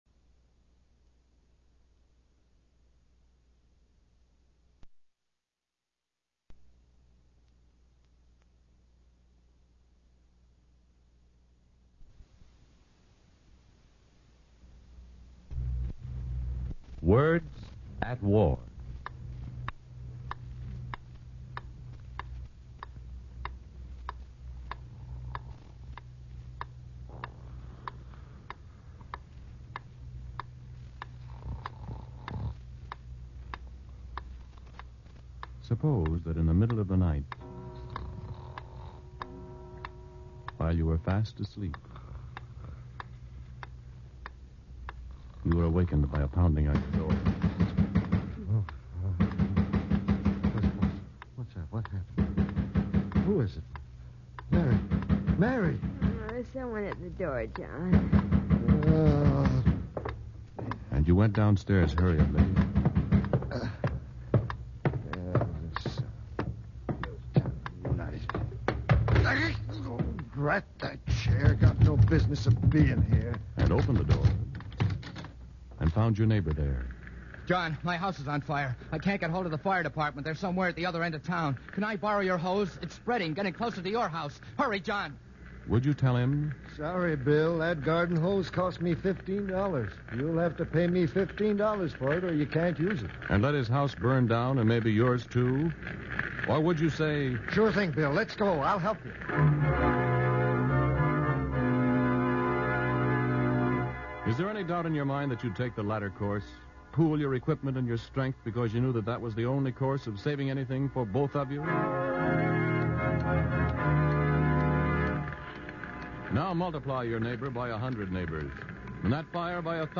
Words At War presents a thrilling and dramatic story, the story of Lend Lease. President Roosevelt sent a message to congress telling what Lend Lease has accomplished to date, explaining its cost and showing how it is helping us win the war.